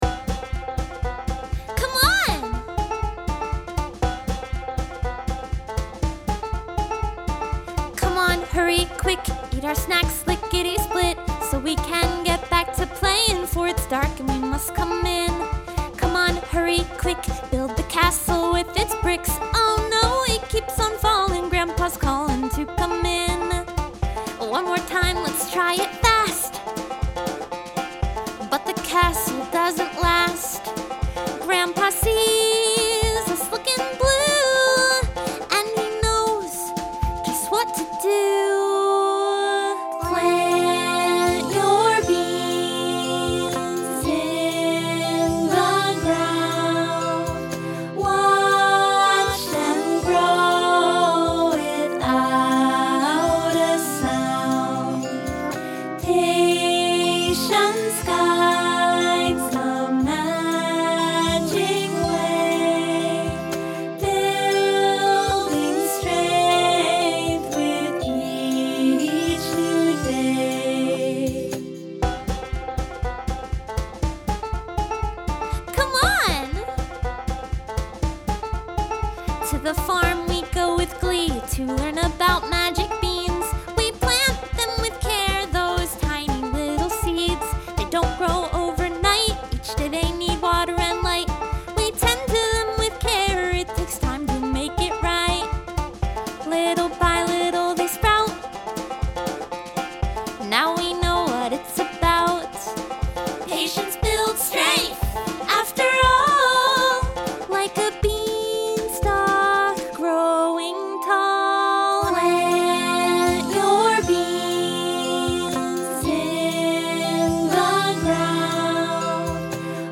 playful